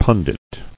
[pun·dit]